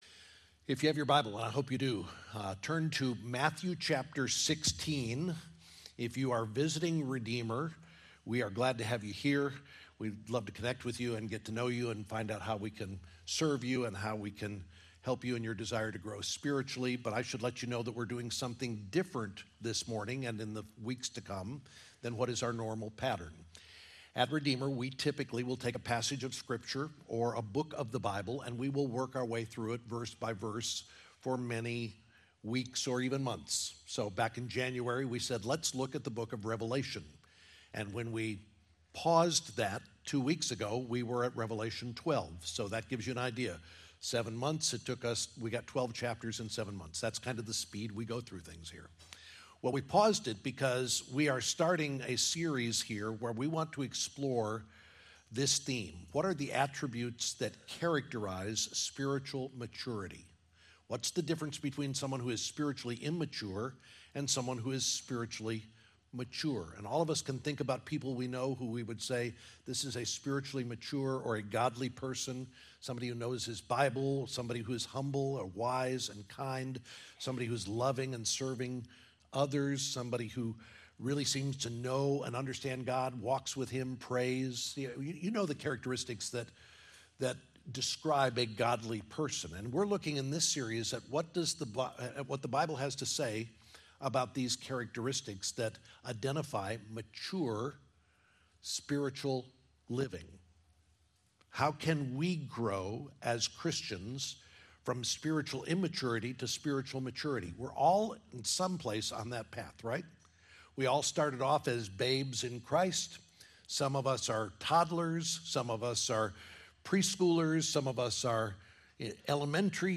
2024 Mature Disciples Colossians 1:28-29 The next sermon in our series about growing as Christians into the maturity God desires for each of us his children focusing on what it means to follow Jesus.